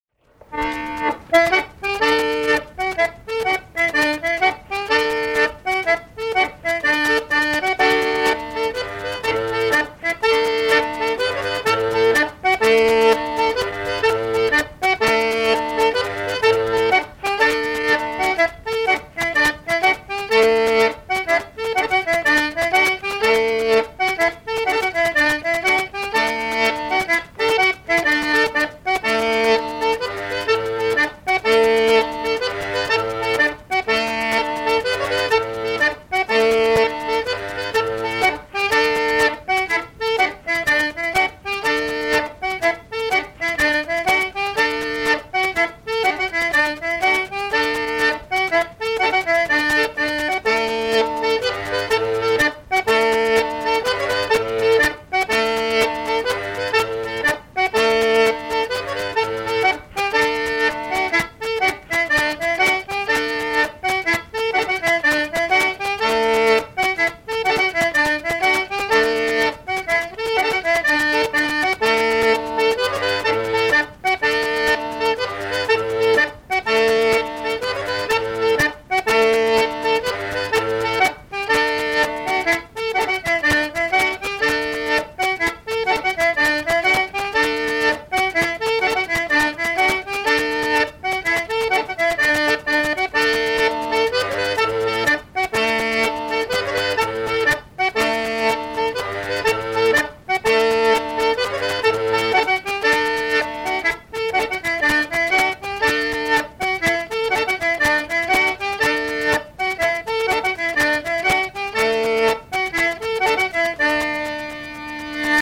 danse : ronde : demi-rond
Enquête Arexcpo en Vendée
Pièce musicale inédite